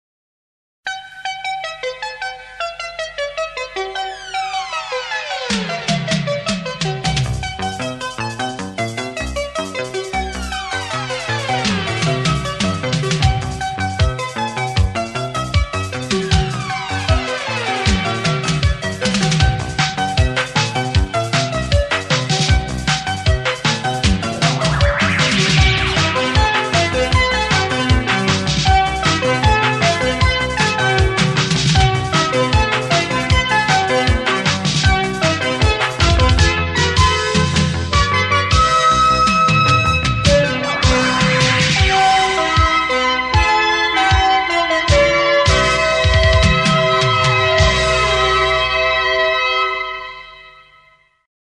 Sintonia de l'emissora